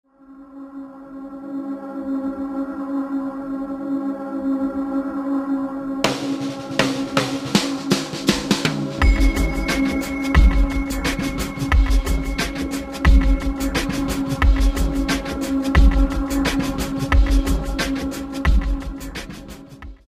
listeners with its hypnotic musical charm.